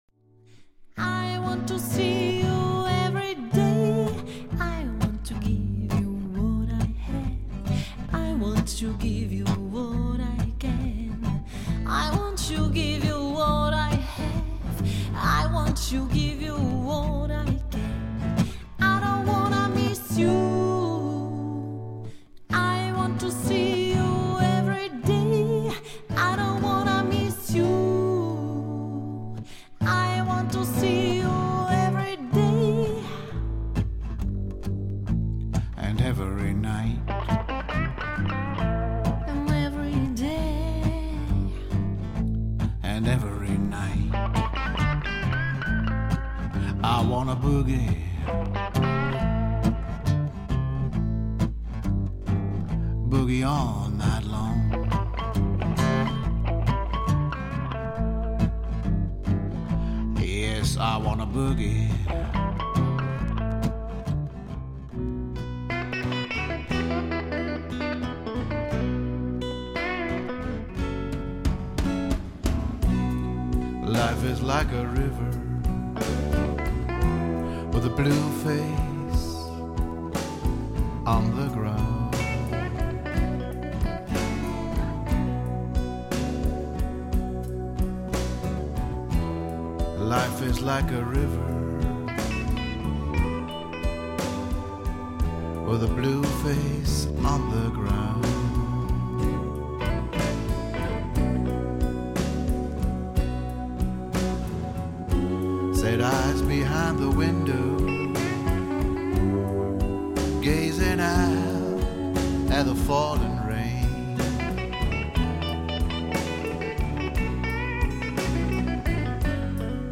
Gesang, Mundharmonika
Gesang, E-Gitarre und Akustik Gitarre
Kontrabass, E-Bass